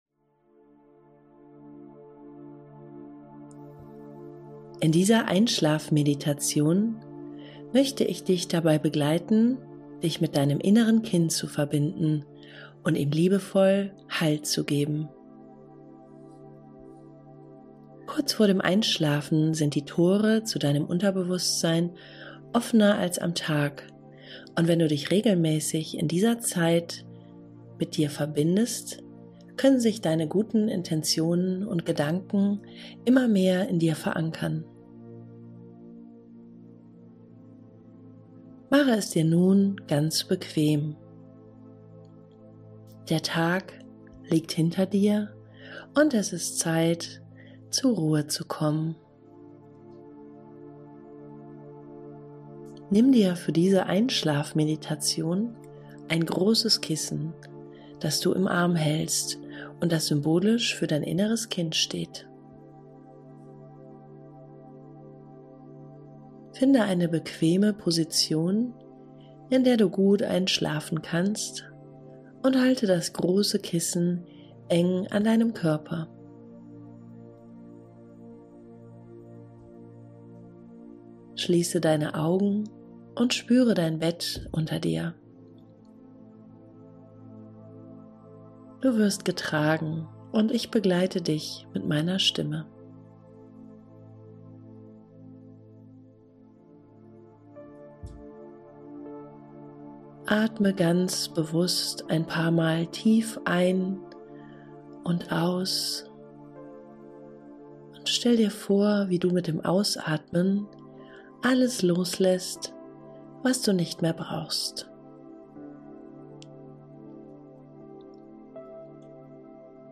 Diese geführte Meditation hilft dir dabei, abends zur Ruhe zu kommen, Gedanken zu beruhigen und dich liebevoll mit deinem inneren Kind zu verbinden. Schaffe Halt und Geborgenheit in dir, um entspannt einzuschlafen.
Meditation_zum_Einschlafen___Heilung_und_Geborgenheit_fuer_das_innere_Kind.mp3